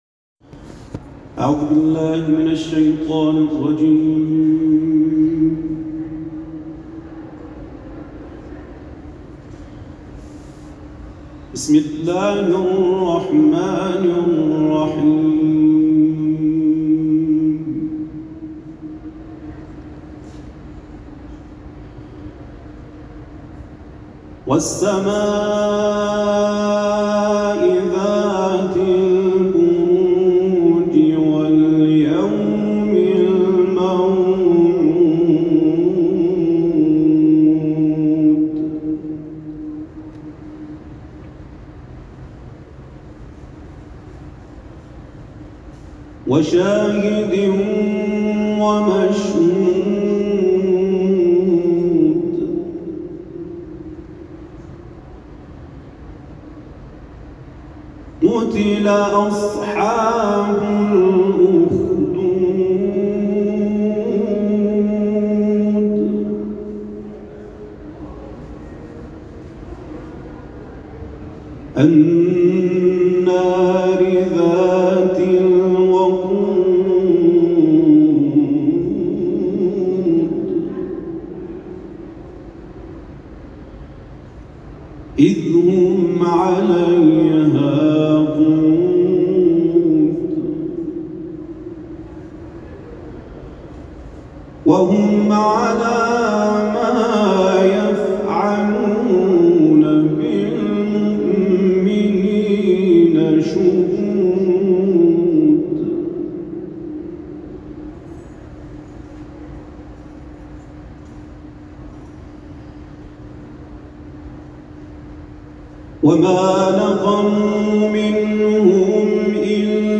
جدیدترین تلاوت قاری ممتاز کشورمان از سوره‌ بروج ارائه می‌شود.
تلاوت قرآن